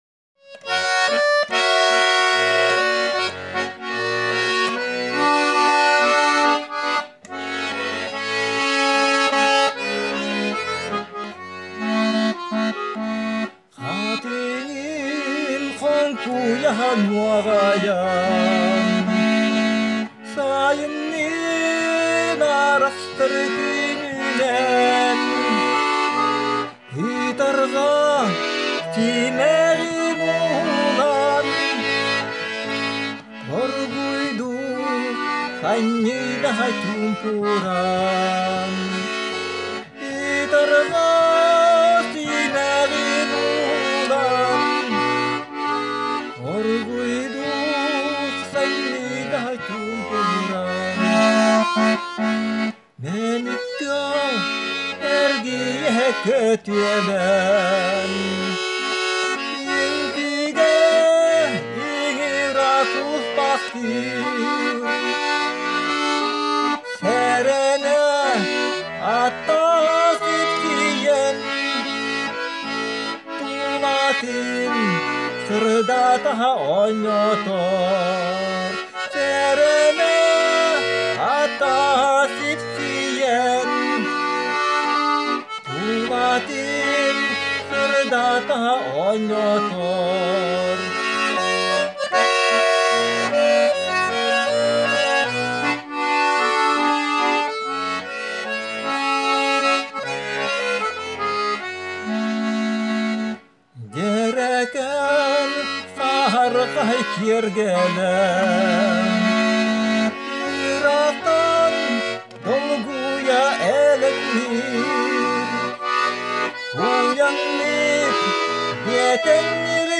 Минусовки